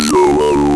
A Go application to generate numbers station like audio output